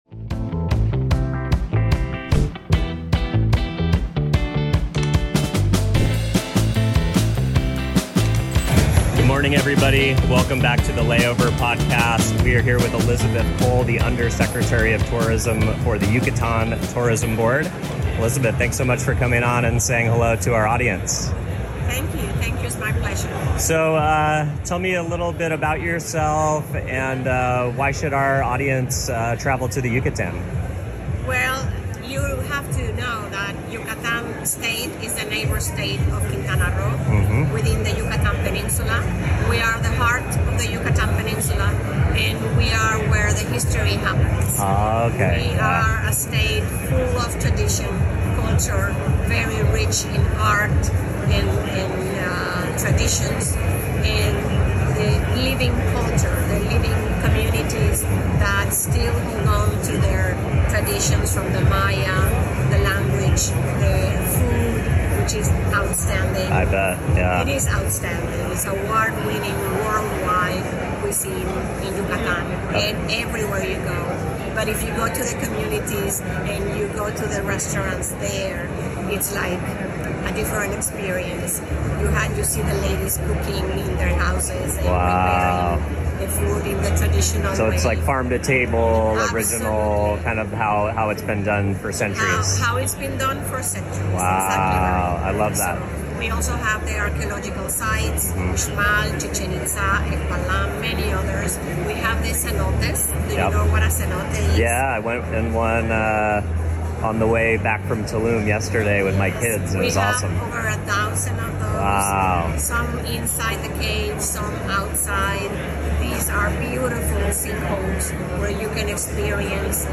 In this episode of The Layover, we sit down with Elizabeth Cole, Undersecretary of Tourism for the Yucatán, to uncover why this storied region is quickly becoming one of Mexico’s most compelling travel destinations.